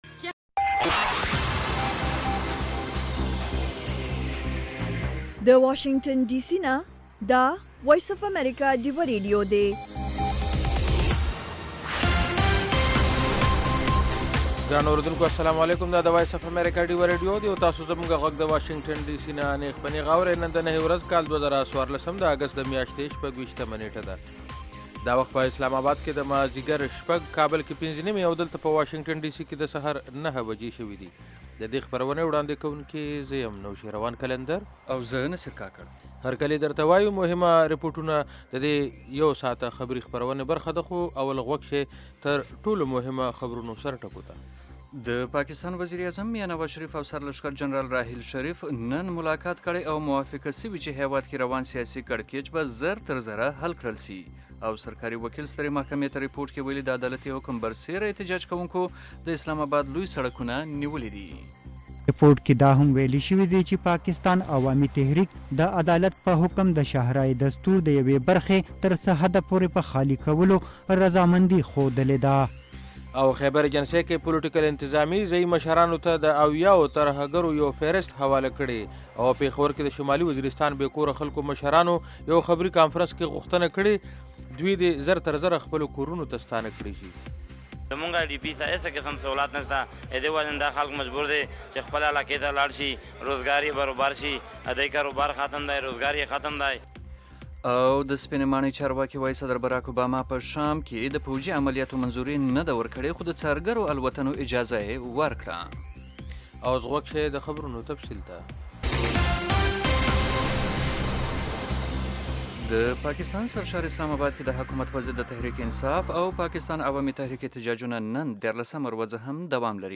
خبرونه - 1300
د وی او اې ډيوه راډيو ماښامنۍ خبرونه چالان کړئ اؤ د ورځې د مهمو تازه خبرونو سرليکونه واورئ.